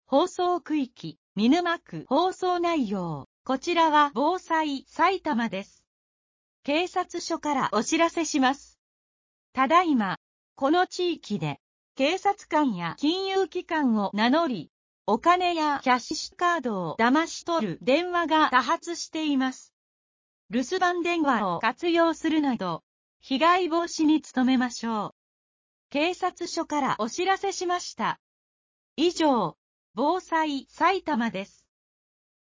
さいたま市防災行政無線／振込詐欺防止啓発放送 | 埼玉県さいたま市メール配信サービス